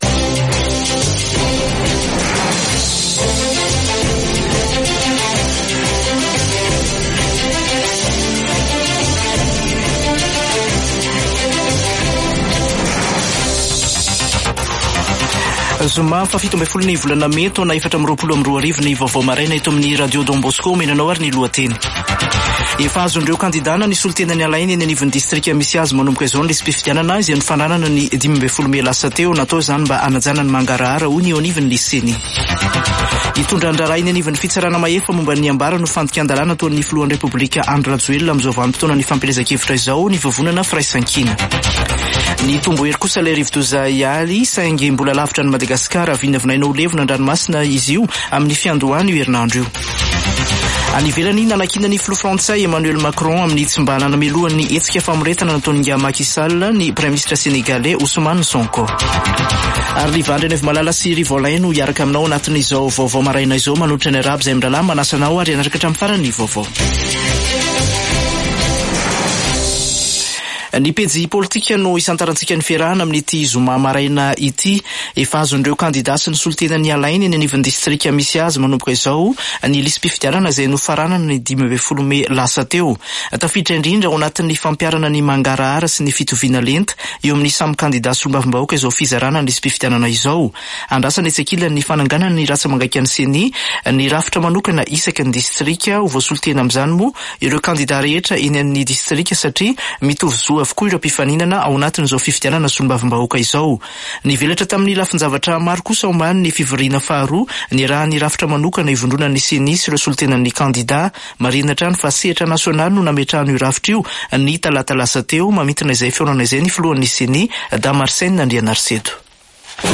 [Vaovao maraina] Zoma 17 mey 2024